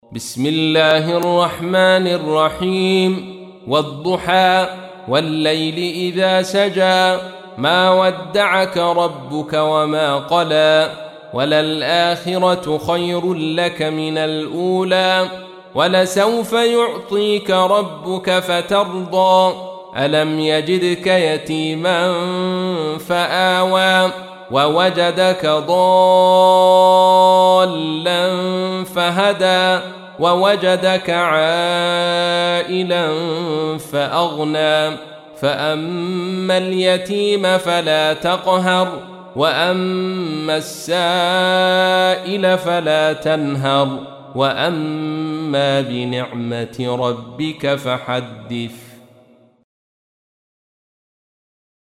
تحميل : 93. سورة الضحى / القارئ عبد الرشيد صوفي / القرآن الكريم / موقع يا حسين